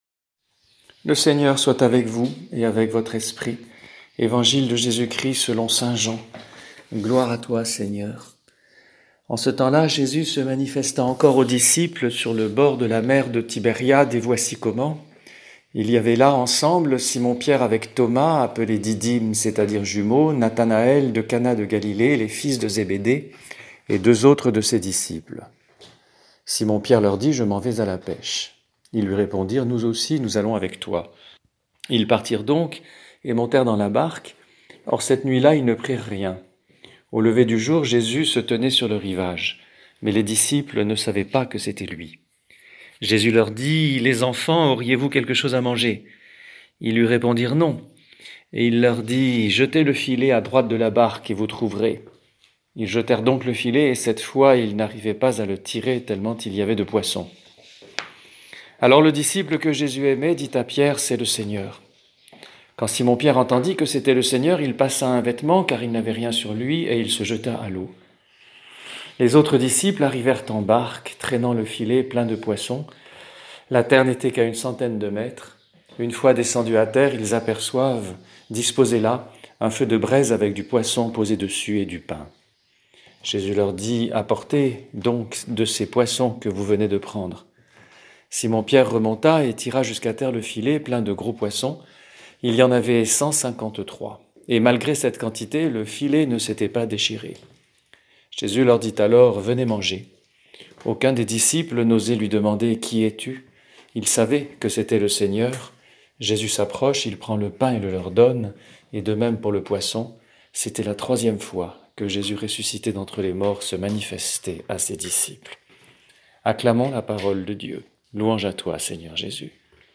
Homélie prononcée le 18 avril 2020
Homélie